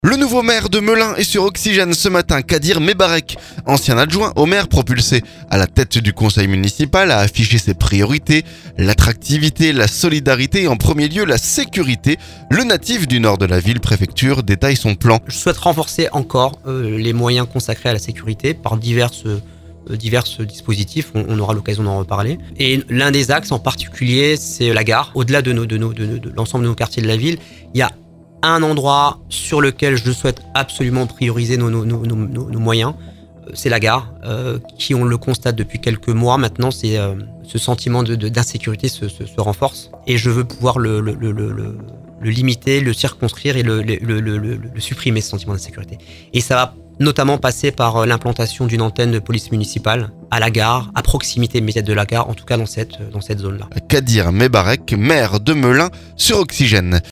Le nouveau maire de Melun est sur Oxygène ce lundi. Kadir Mebarek, ancien adjoint au maire propulsé à la tête du conseil municipal, a affiché ses priorités : l'attractivité, la solidarité et en premier lieu la sécurité.